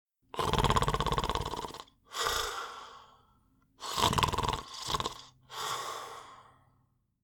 Download Free Snoring Sound Effects | Gfx Sounds
Deep-snoring-old-man-sleeping-2.mp3